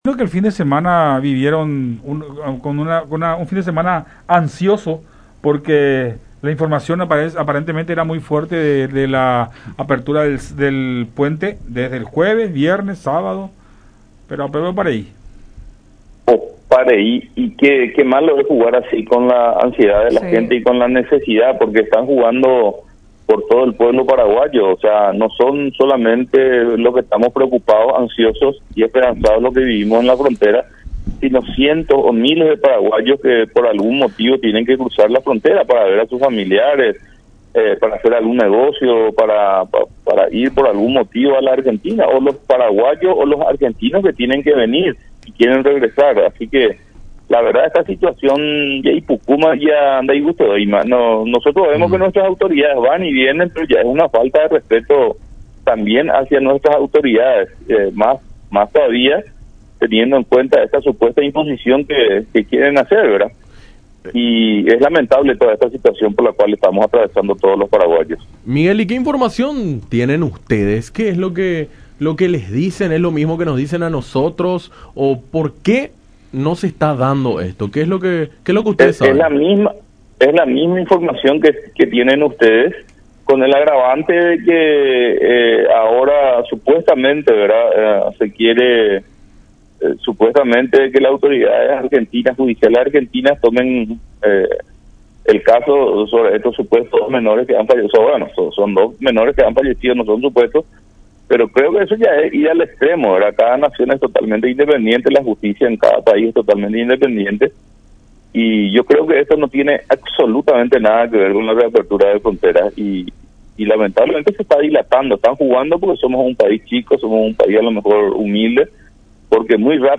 charla con Enfoque 800 por La Unión